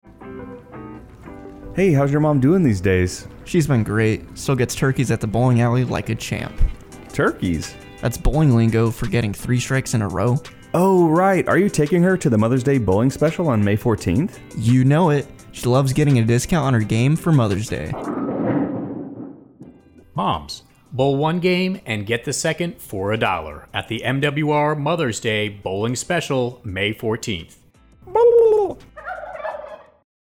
Thirty-second spot highlighting the MWR Mother's Day Bowling event in Manama, Bahrain to be aired on AFN Bahrain’s radio show.